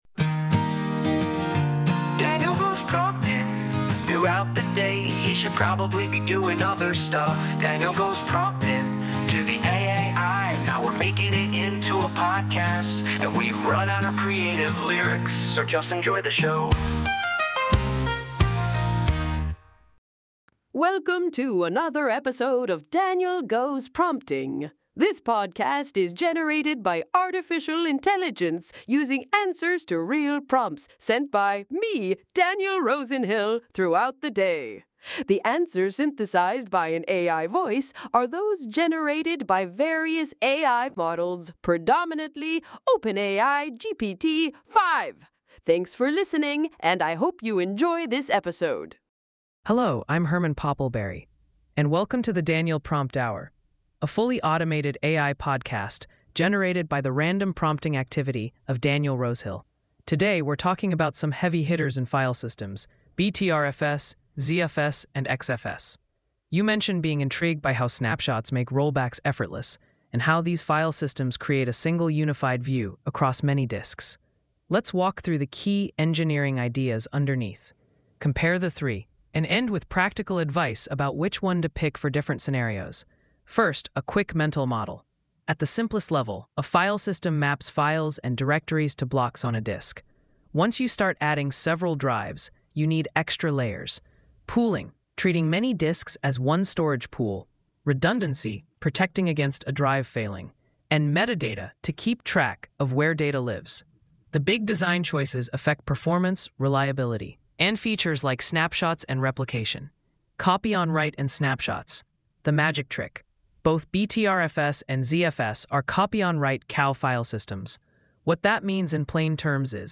AI-Generated Content: This podcast is created using AI personas.
A conversational deep-dive into how Btrfs, ZFS, and XFS approach multi-disk storage, snapshots, and data integrity.